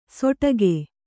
♪ soṭage